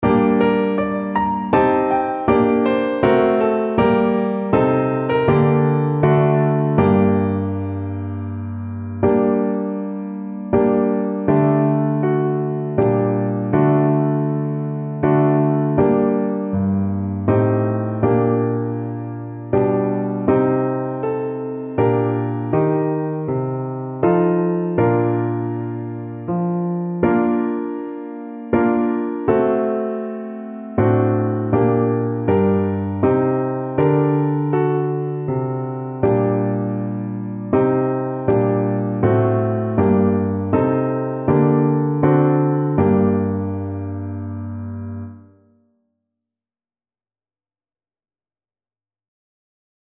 Clarinet
G minor (Sounding Pitch) A minor (Clarinet in Bb) (View more G minor Music for Clarinet )
3/4 (View more 3/4 Music)
Andante
Traditional (View more Traditional Clarinet Music)